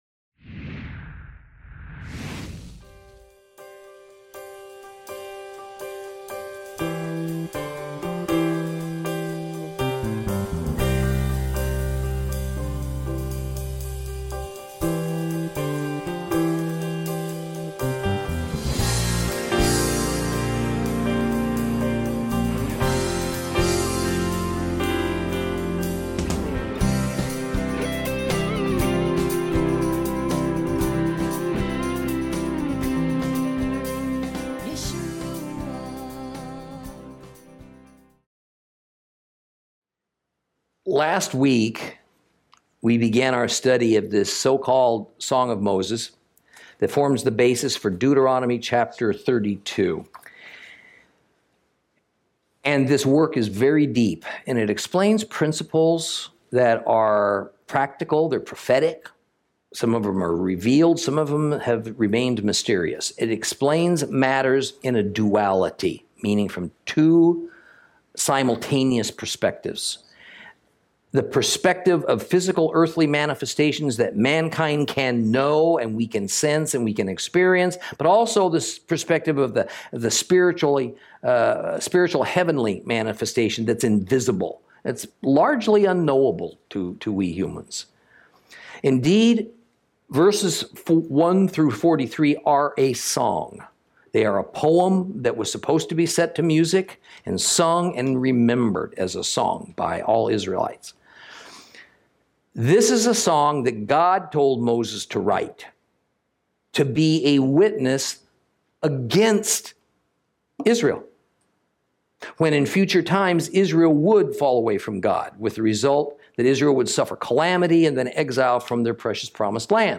Lesson 45 – Deuteronomy 32 Cont.